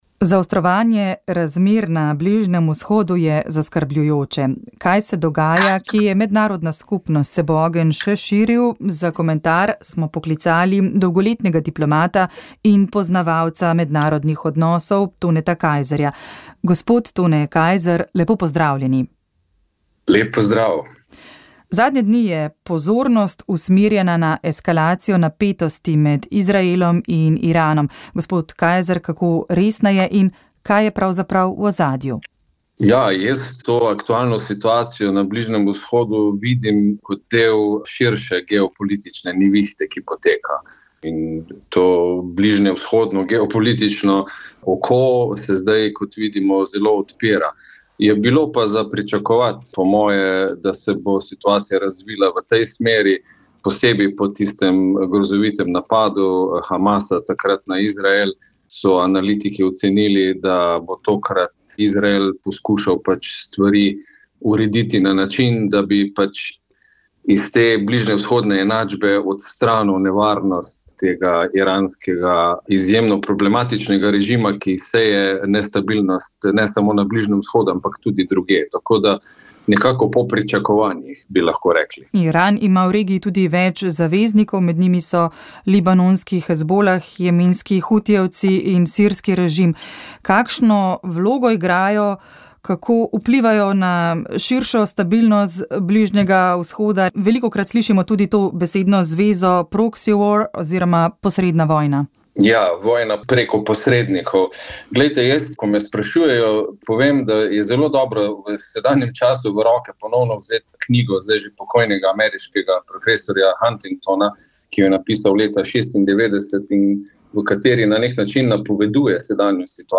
Informativni prispevki